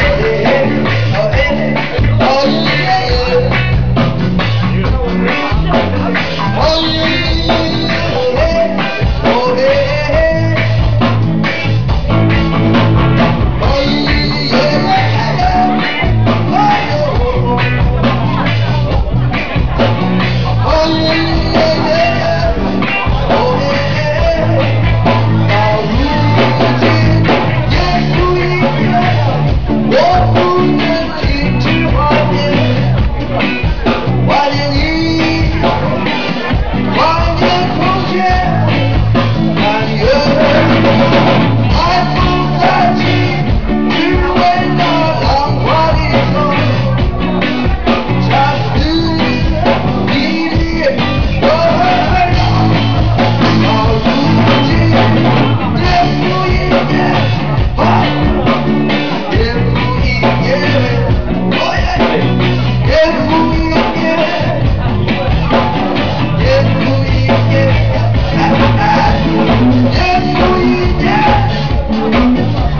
A Chinese guy who can handle indie rock and reggae?
Listen to a clip of one of the bands at Tang Hui from March 12, 2005 (65 second WAV file, 784KB).